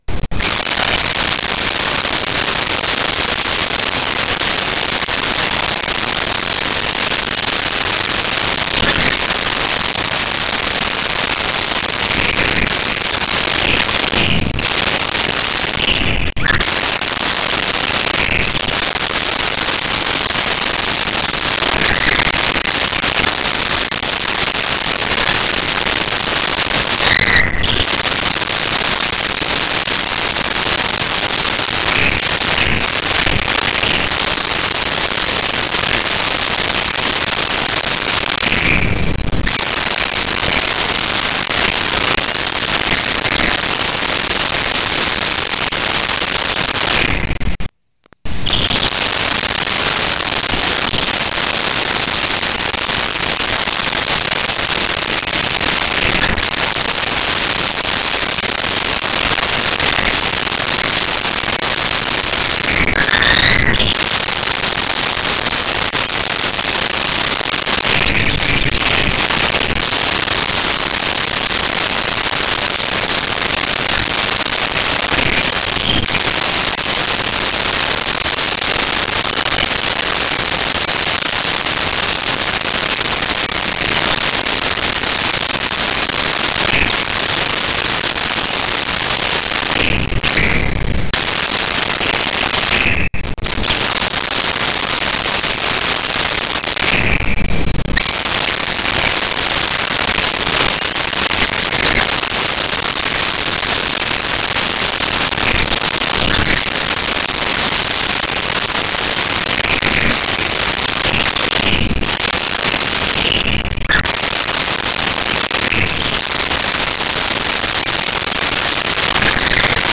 Birds Songs And Calls
Birds Songs And Calls.wav